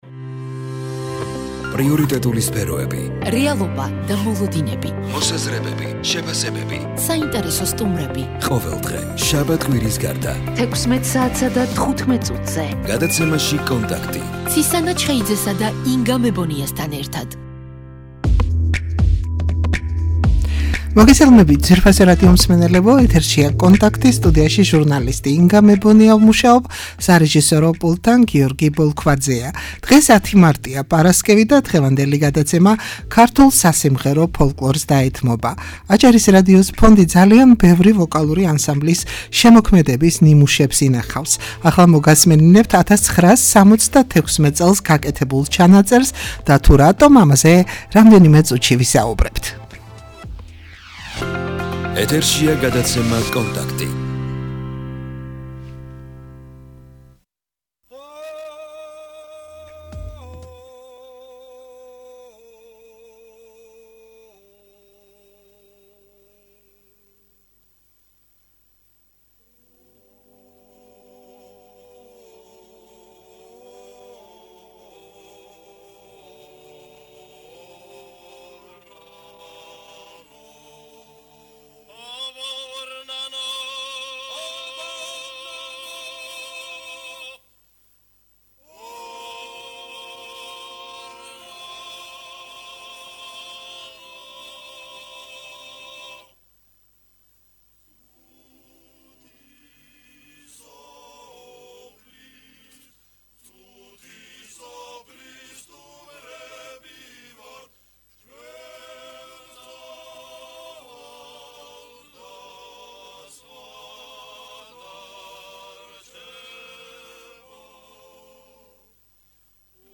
ვოკალური ანსამბლი "ორნანო"